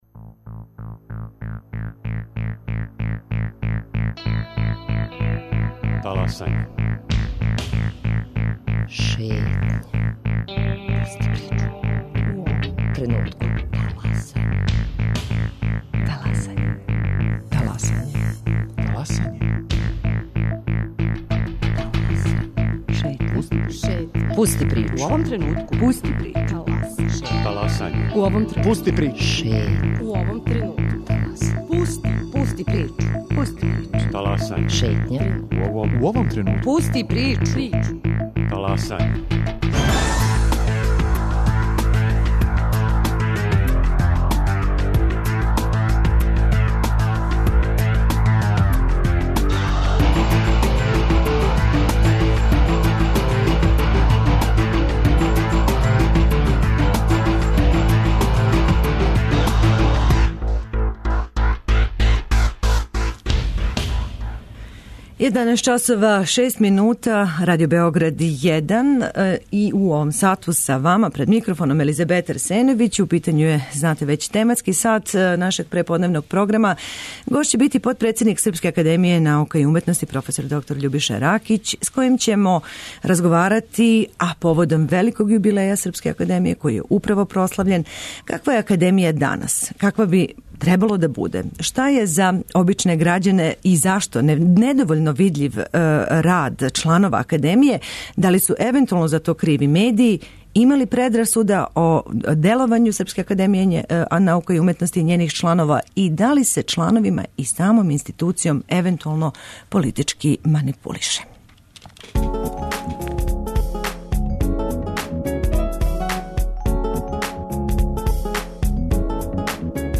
У тематском сату гост ће бити потпредседник САНУ проф. др Љубиша Ракић с којим ћемо разговарати о томе каква је академија данас и каква би требало да буде, зашто је за обичне грађане недовољно видљив рад чланова академије и да ли су за то криви медији?